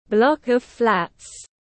Block of flats /ˌblɒk əv ˈflæts/
Block-of-flats.mp3